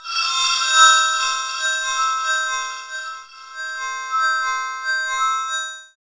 SI2 CHIME06L.wav